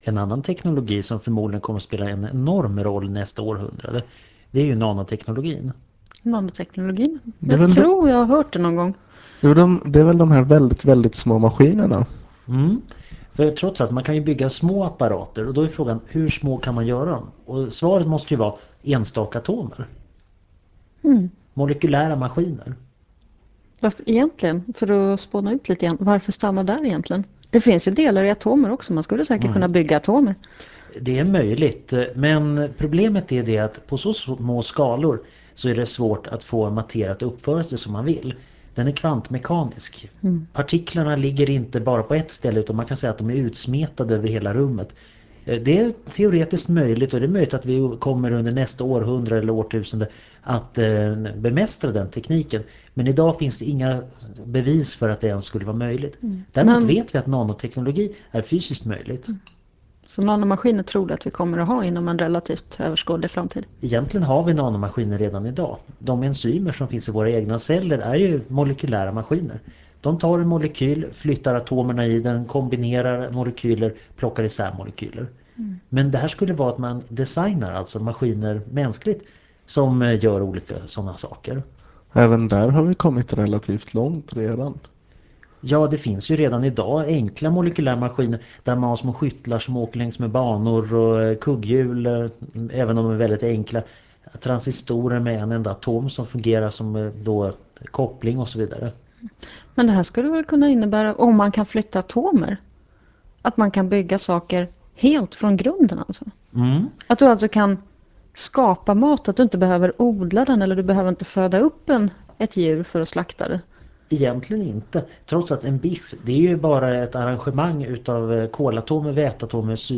Föredraget handlar om teknik och sänds januari 2000 i Etervåg.